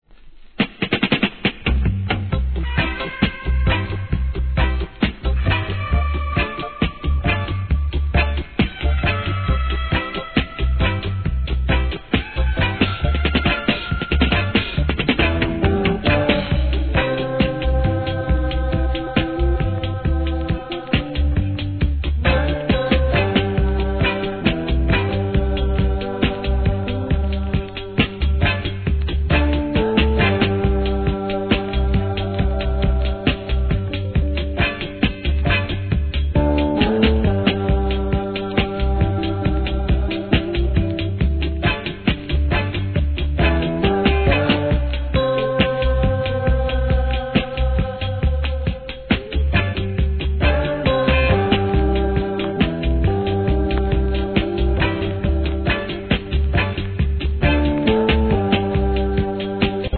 REGGAE
怒渋なプロダクション